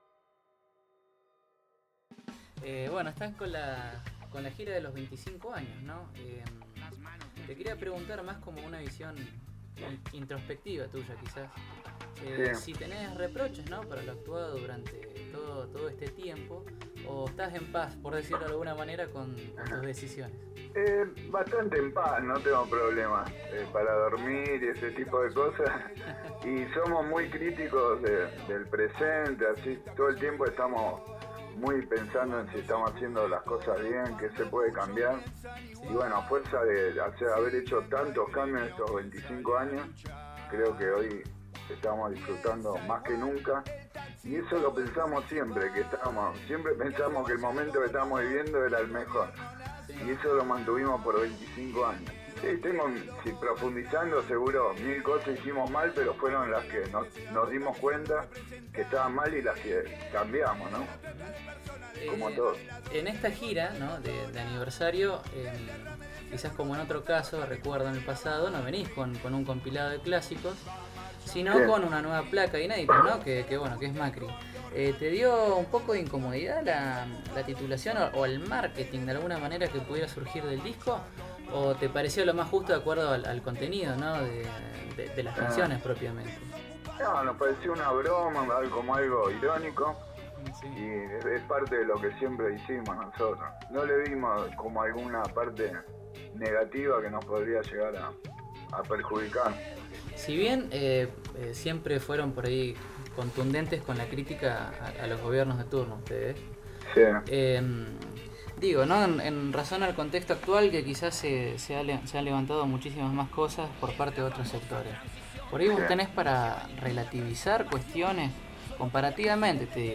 Entrevista-las-manos-de-filippi.mp3